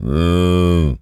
cow_moo_07.wav